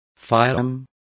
Complete with pronunciation of the translation of firearm.